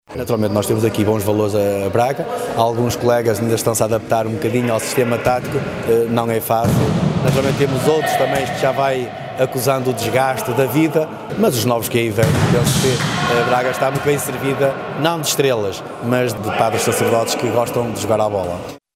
Declarações